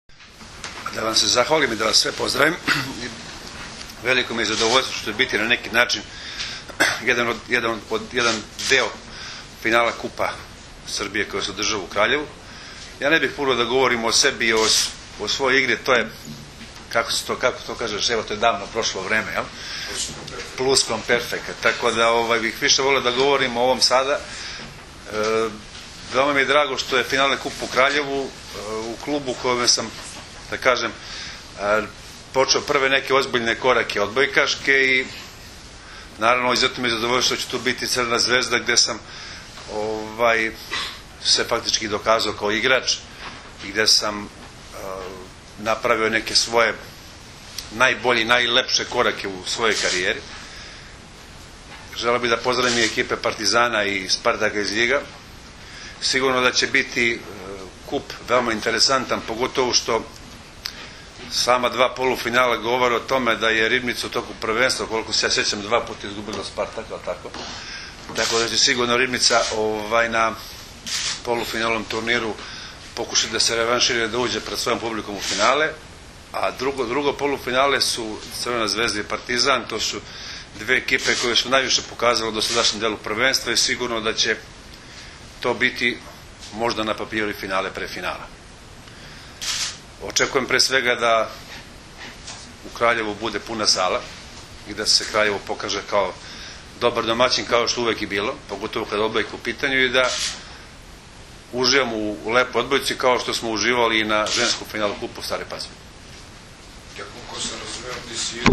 U prostorijama Odbojkaškog saveza Srbije danas je održana konferencija za novinare povodom Finalnog turnira 49. Kupa Srbije u konkurenciji odbojkaša, koji će se u subotu i nedelju odigrati u Hali sportova u Kraljevu.